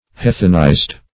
Heathenized - definition of Heathenized - synonyms, pronunciation, spelling from Free Dictionary
heathenized.mp3